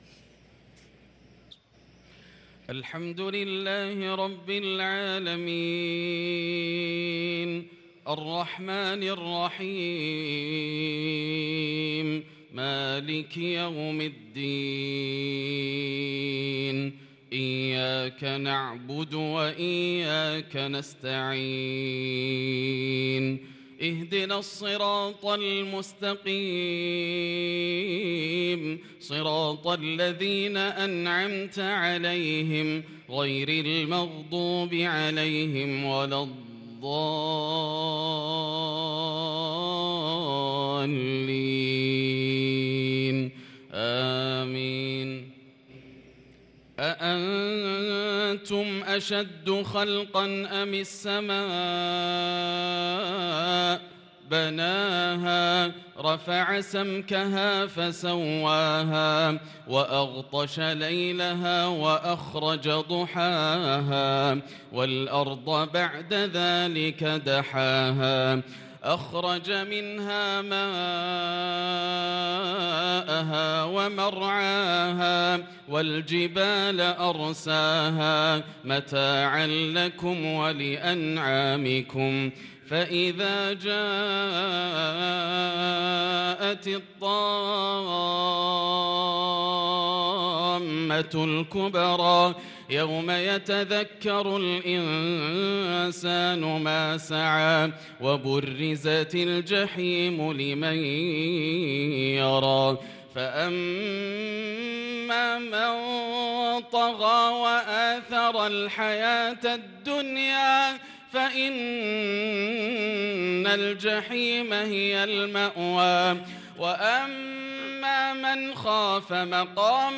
صلاة المغرب للقارئ ياسر الدوسري 8 رمضان 1443 هـ
تِلَاوَات الْحَرَمَيْن .